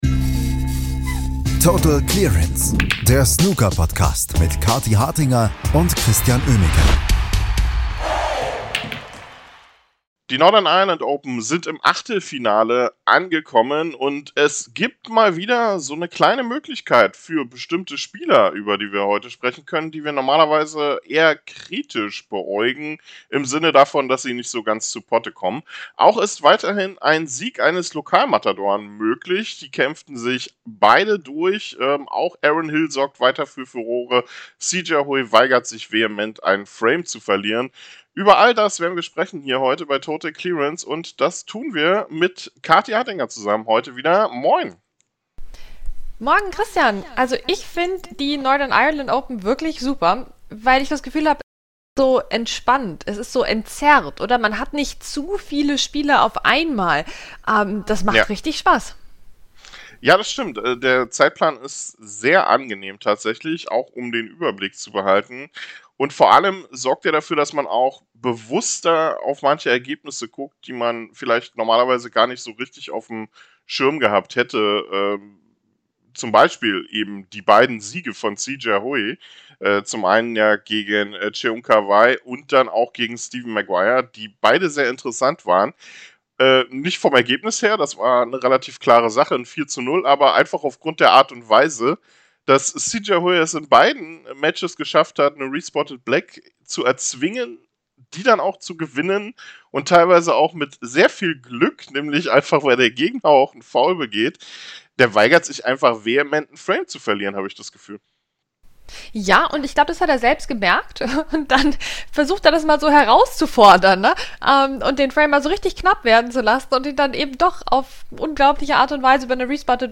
der deutsche Snooker-Podcast von Fans für Fans